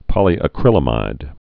(pŏlē-ə-krĭlə-mīd)